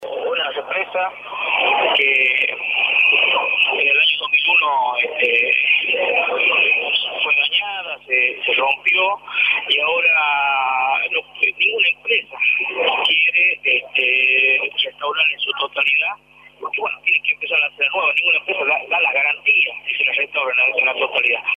marcelo-del-moral-intendente-de-castro-barros-por-radio-la-red.mp3